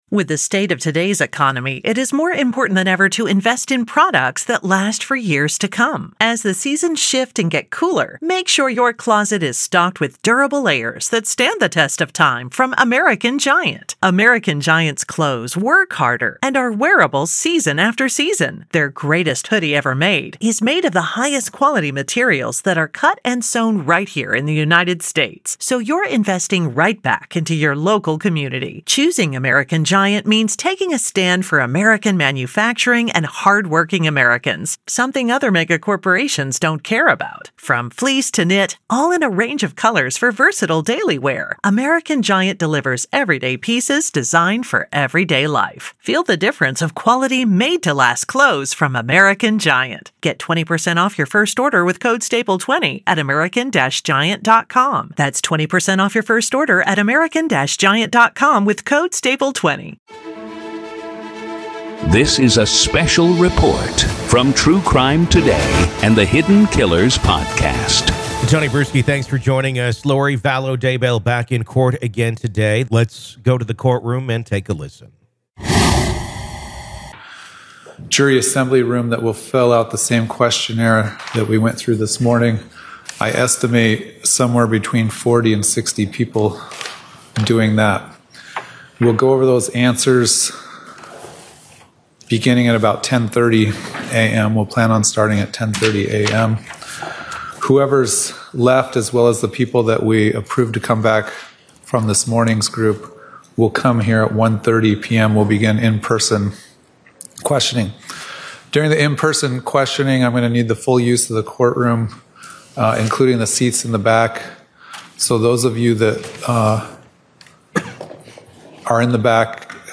COURT AUDIO: AZ VS LORI DAYBELL DAY 1 JURY SELECTION PART 1
In a heated hearing held Monday afternoon in Arizona, Lori Vallow Daybell passionately argued motions in her ongoing case.